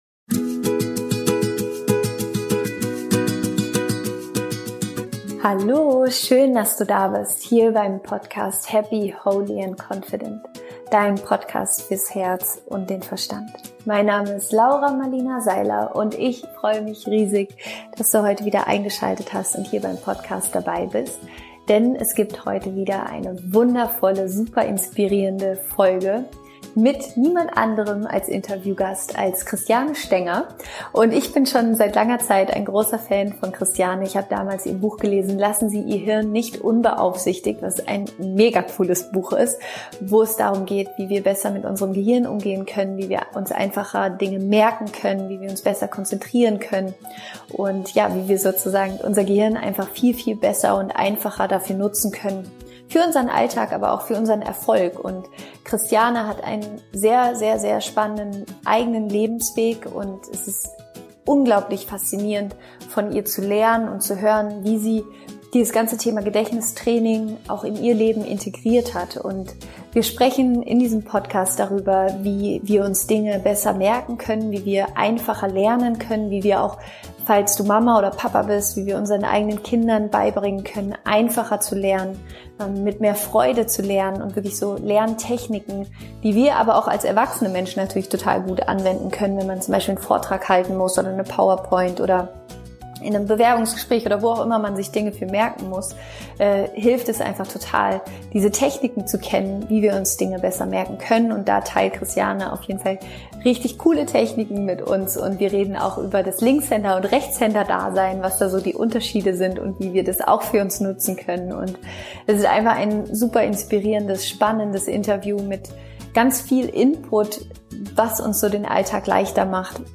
Wie du nie wieder etwas Wichtiges vergisst - Interview Special